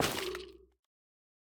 Minecraft Version Minecraft Version latest Latest Release | Latest Snapshot latest / assets / minecraft / sounds / block / sculk_vein / break2.ogg Compare With Compare With Latest Release | Latest Snapshot